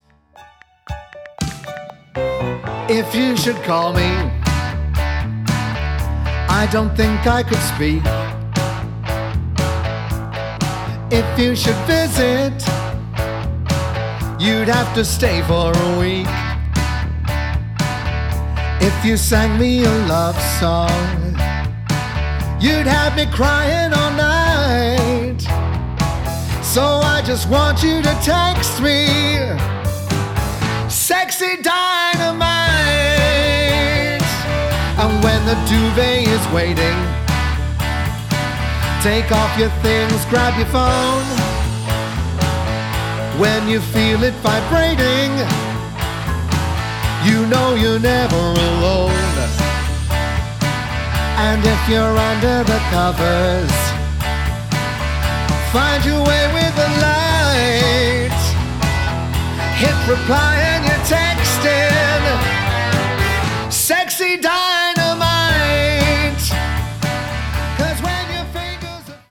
These are performed in similar styles to the finished versions but have different orchestrations and keys.